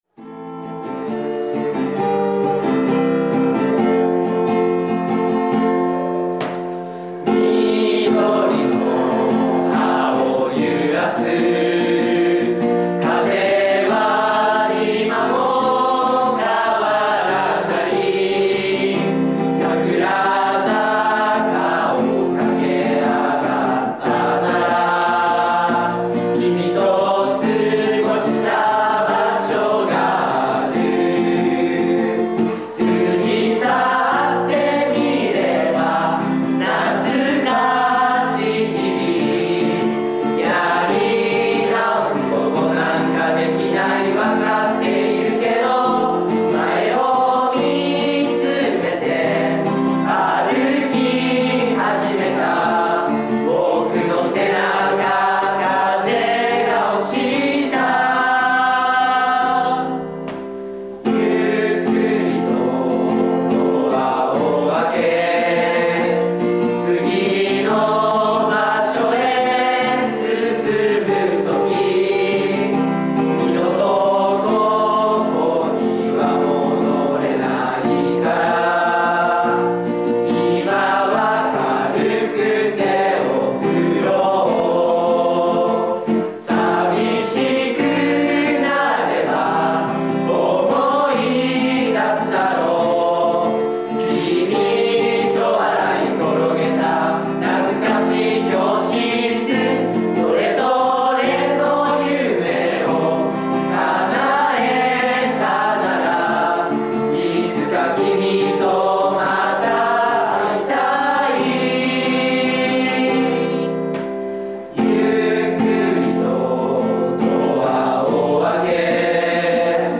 さて，CDの曲ですが癒されます。温かなギターと歌声が聴こえてきます。